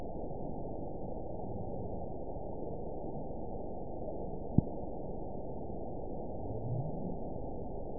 event 921989 date 12/24/24 time 20:22:47 GMT (5 months, 4 weeks ago) score 9.18 location TSS-AB04 detected by nrw target species NRW annotations +NRW Spectrogram: Frequency (kHz) vs. Time (s) audio not available .wav